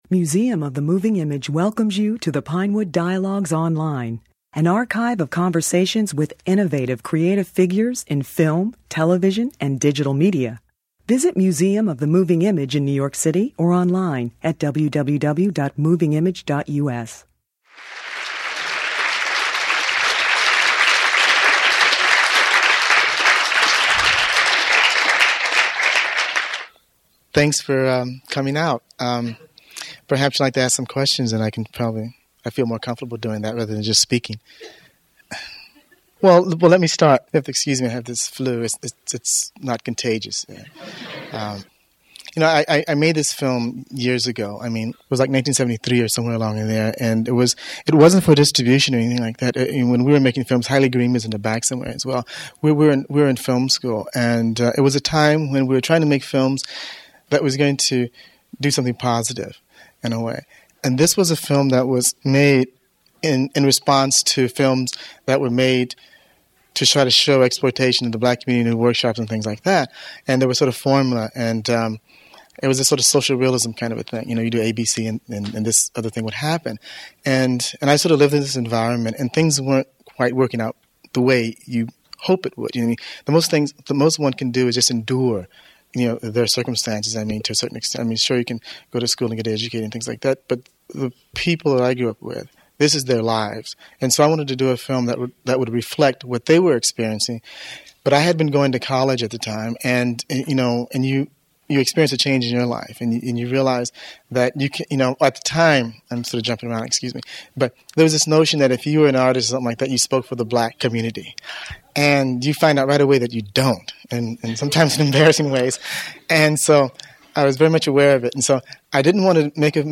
During a retrospective of his films at the Museum of the Moving Image, he introduced a screening of Killer of Sheep and then participated in a wide-ranging discussion moderated by culture critic Greg Tate.